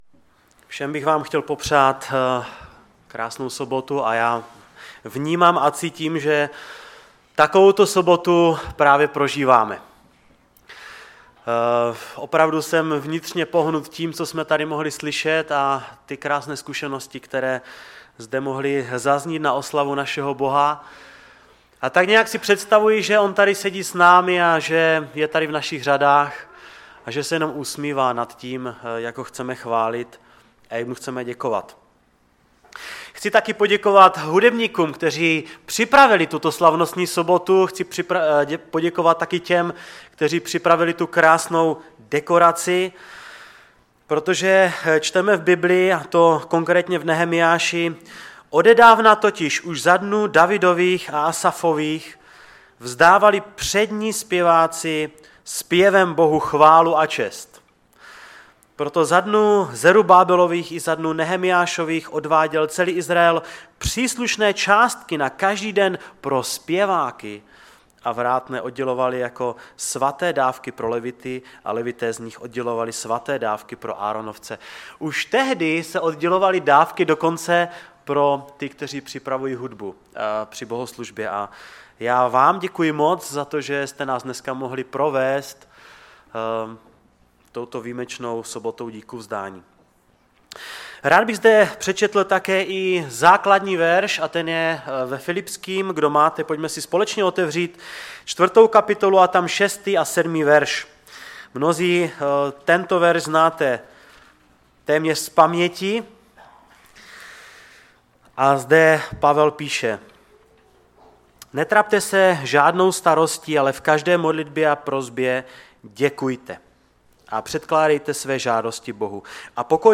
Kázání
ve sboře Ostrava-Radvanice v rámci Soboty Díkuvzdání.